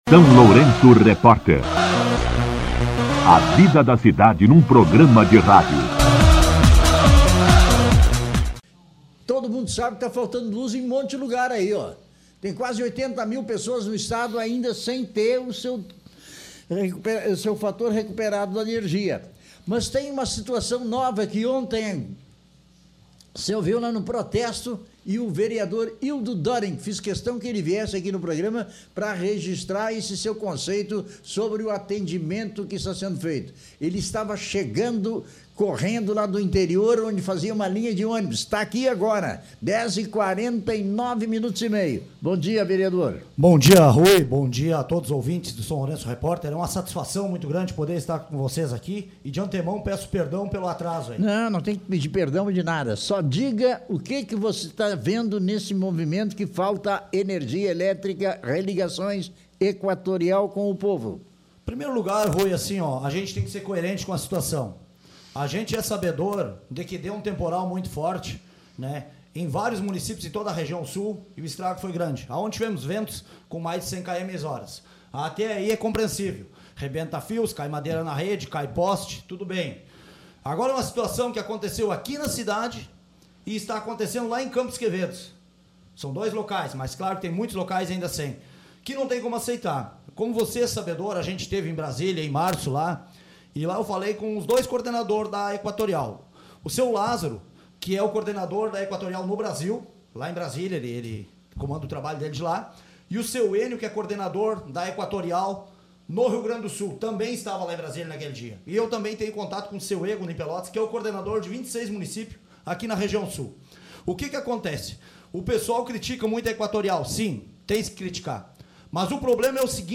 O vereador Ildo Döring (PT) fez uma grave denúncia durante entrevista ao SLR RÁDIO, apontando supostas irregularidades no trabalho das empresas terceirizadas que prestam serviço à CEEE Equatorial.